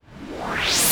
VEC3 Reverse FX
VEC3 FX Reverse 02.wav